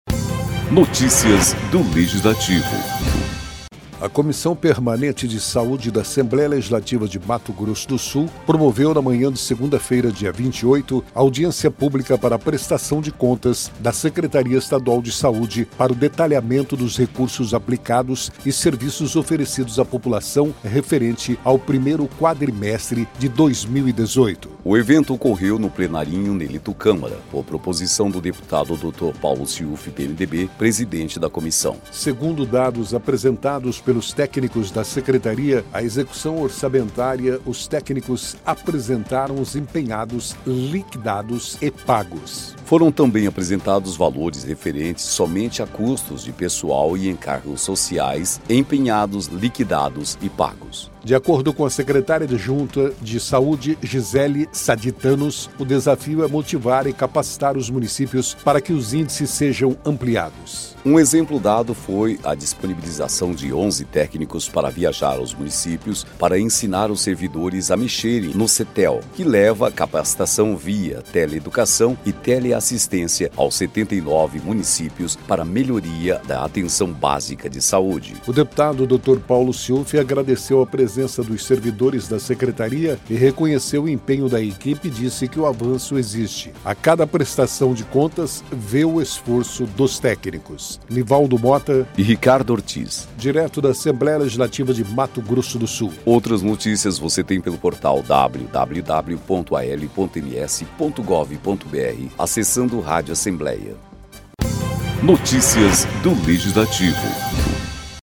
Locução